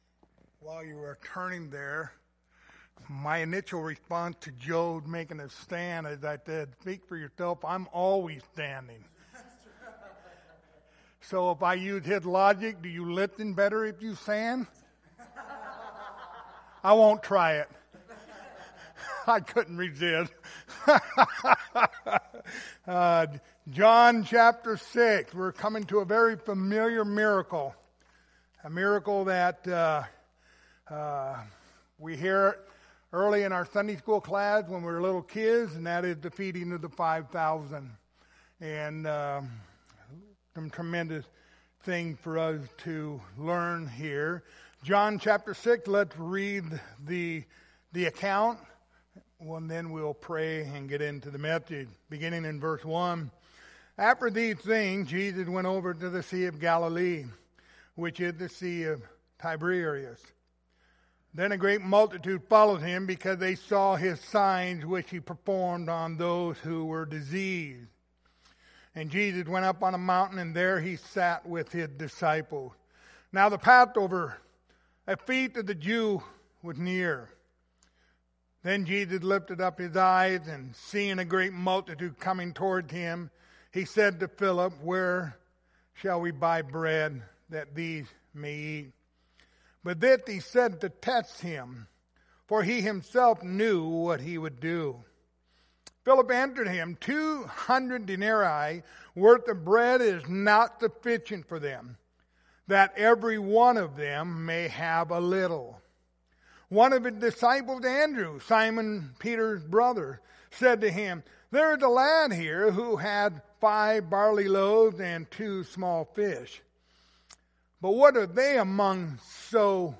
The Gospel of John Passage: John 6:1-13 Service Type: Wednesday Evening Topics